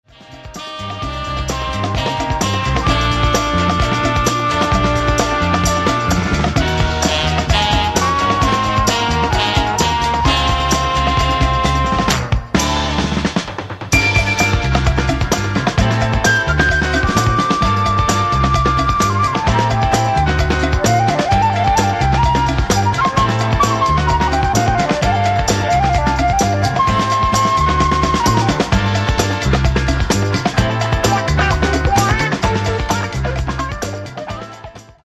Genre:   Latin Disco Soul